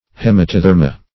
Hematotherma - definition of Hematotherma - synonyms, pronunciation, spelling from Free Dictionary
Search Result for " hematotherma" : The Collaborative International Dictionary of English v.0.48: Hematotherma \Hem`a*to*ther"ma\, n. pl.